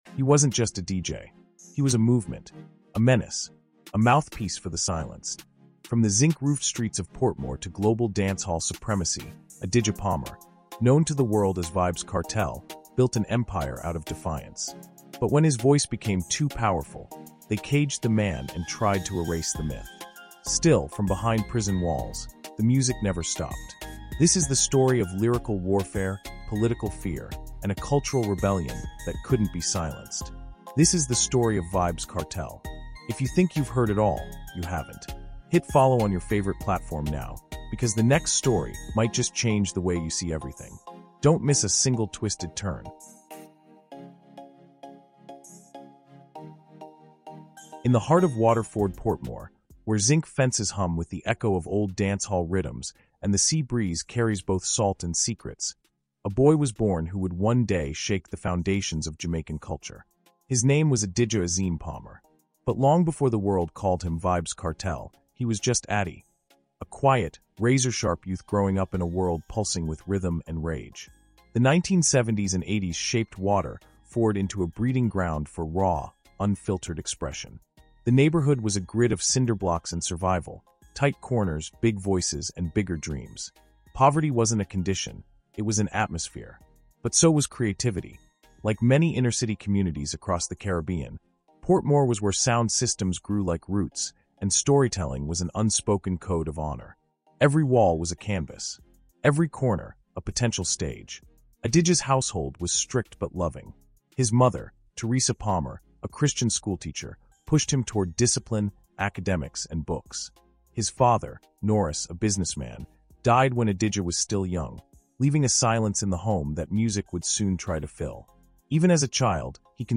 CARIBBEAN HISTORY: Vybz Kartel — The Voice They Tried to Silence is a gripping, emotionally immersive audiobook documentary exploring the life, trial, and cultural revolution led by Adidja Palmer — known to the world as Vybz Kartel. From the zinc-roof streets of Portmore to the stages of global dancehall dominance, Kartel redefined Caribbean music, reshaped Jamaican music, and challenged narratives in Caribbean history and black history alike.